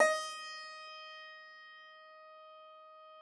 53l-pno15-D3.wav